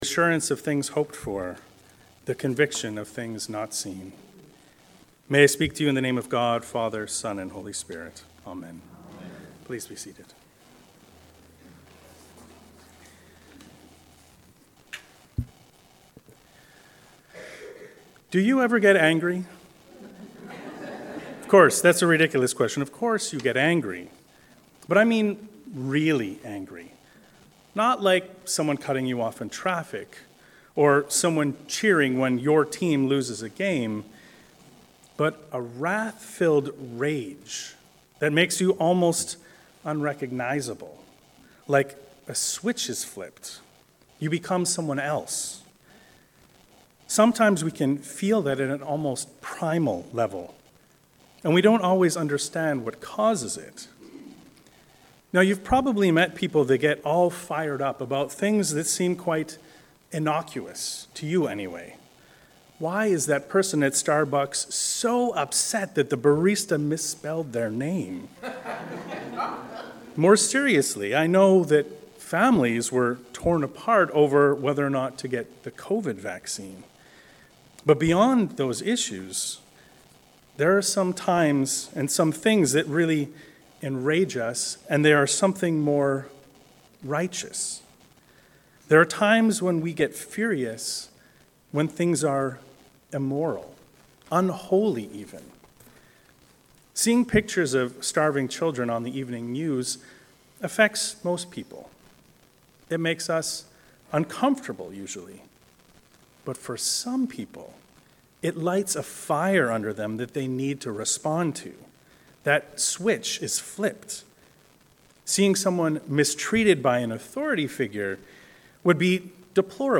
The Wrath of God. A sermon on Isaiah 1, Luke 12, and Hebrews 11